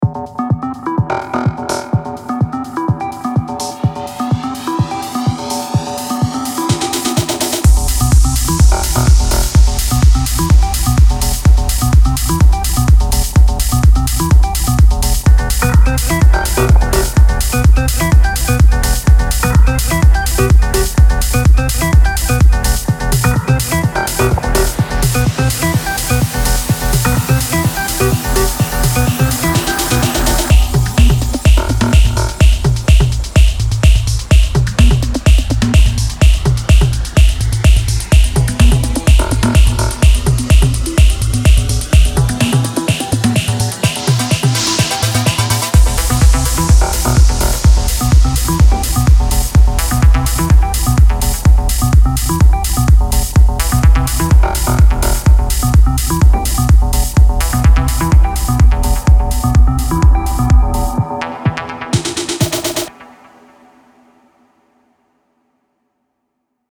مجموعه پرکاشن تکنو